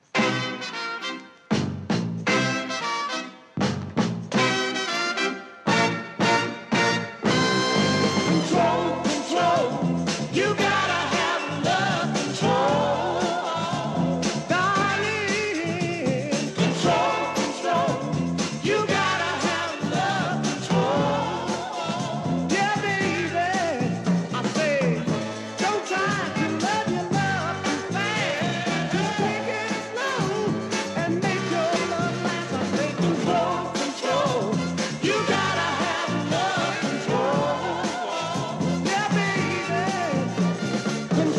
Northern Soul